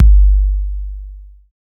808 DEEPK F.wav